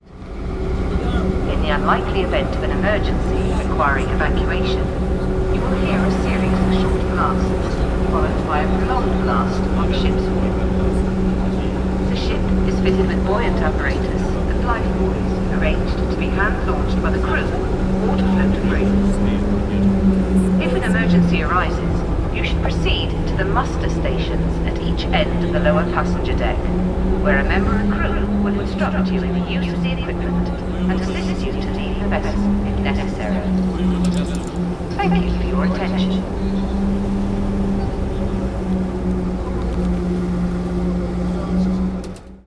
Muster warning on the Woolwich Free Ferry in London